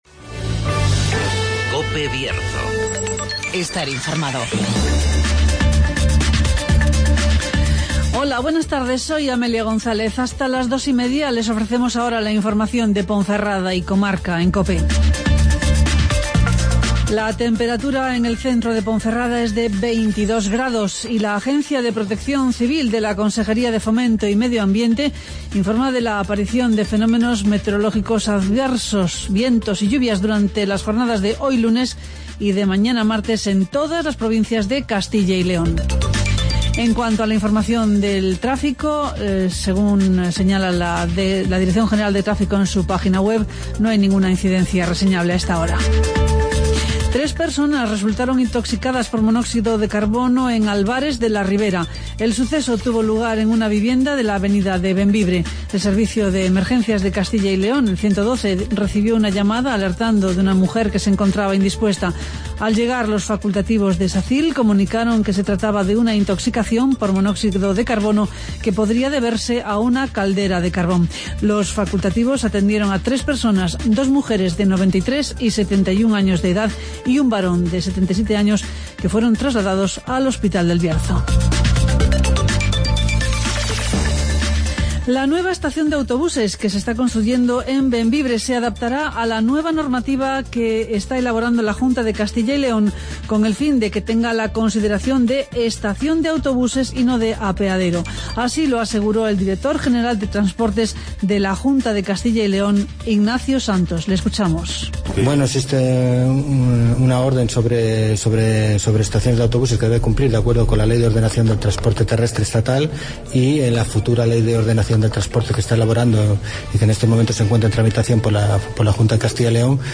Escucha las noticias de Ponferrada y comarca en el Informativo Mediodía de COPE Bierzo